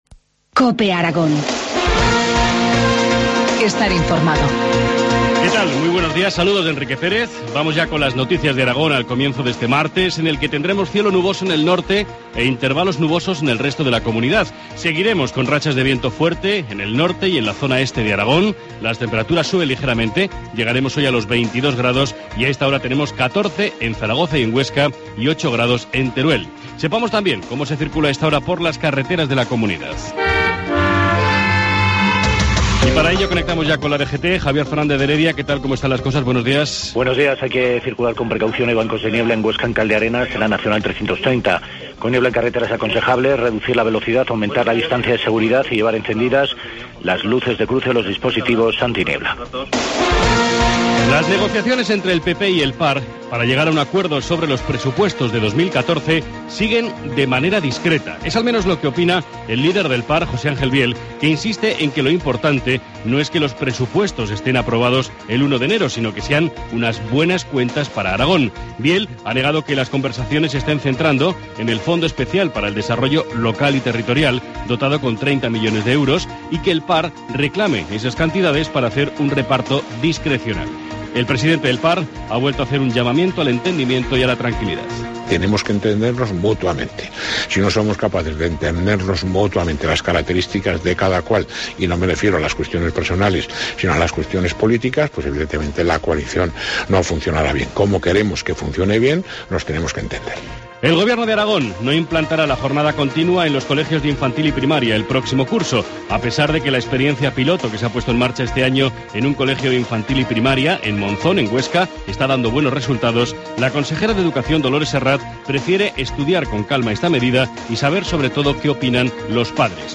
Informativo matinal, martes 5 de noviembre, 7.25 horas